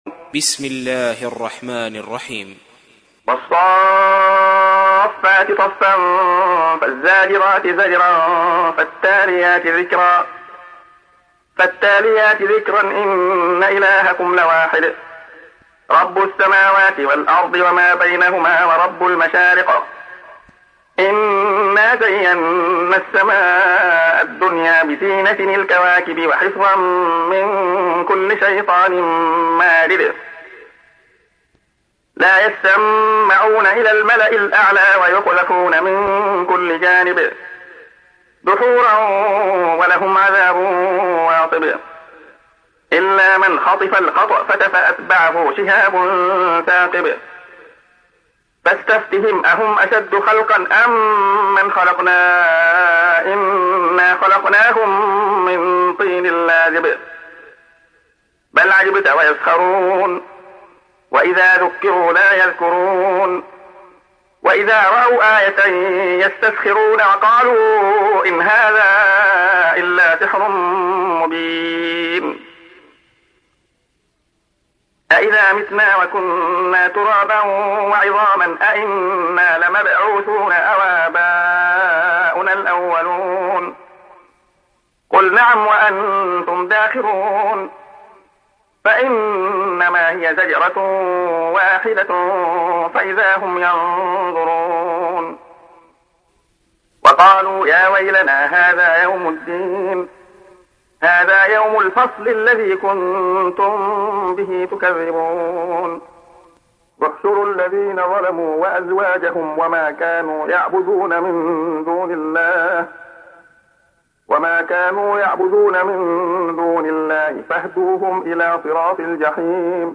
تحميل : 37. سورة الصافات / القارئ عبد الله خياط / القرآن الكريم / موقع يا حسين